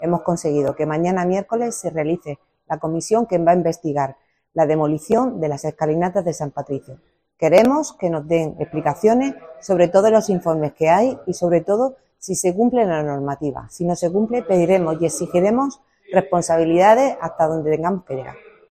María de los Ángeles Mazuecos, concejala del PSOE en Ayuntamiento de Lorca